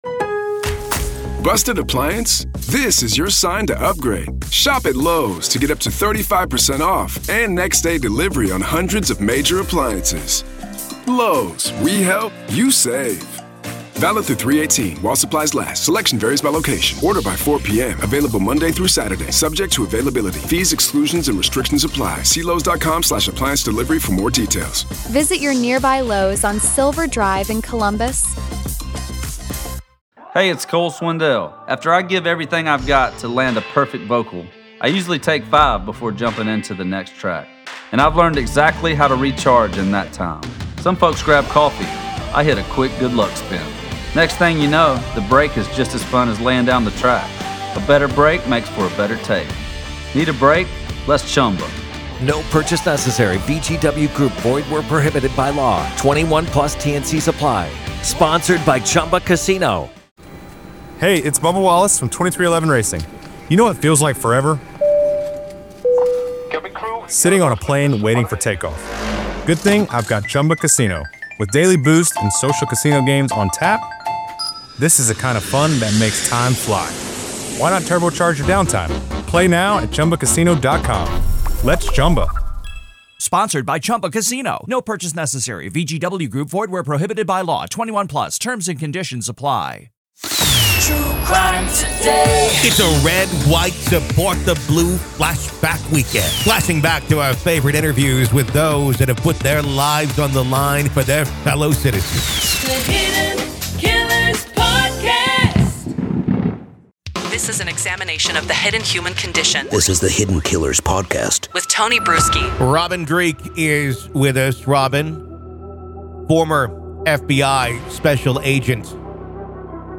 In the Red, White, Salute the Blue Flash-Back Weekend, we pay tribute to those who have dedicated their lives to law enforcement. This event celebrates our favorite interviews from the past, spotlighting the stories, experiences, and insights shared by our esteemed guests from...